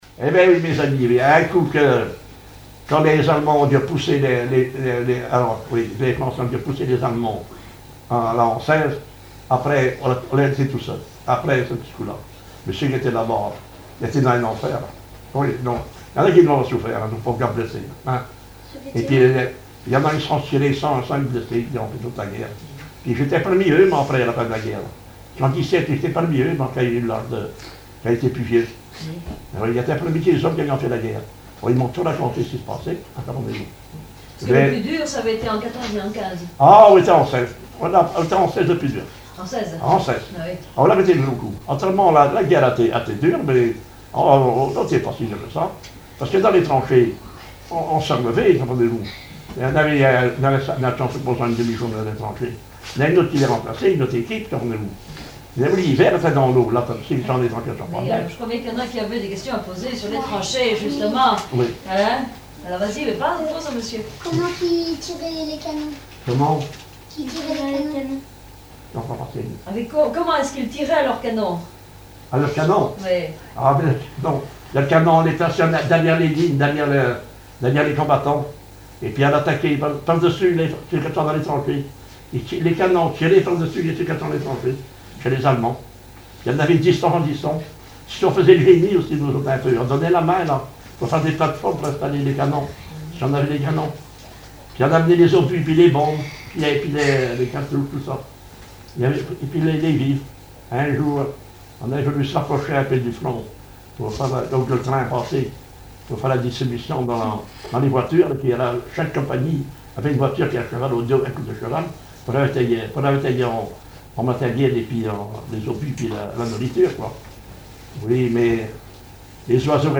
témoignage sur le vécu durant la guerre 1914-1918
Catégorie Témoignage